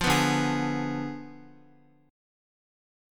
Ddim7 chord